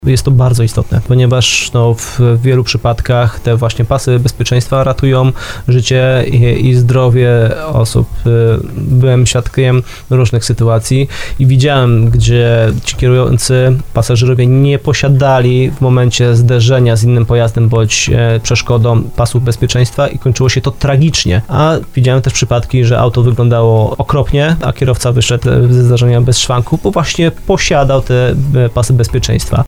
Jak mówił na antenie radia RDN Małoplska w audycji 'Pomagamy i chronimy”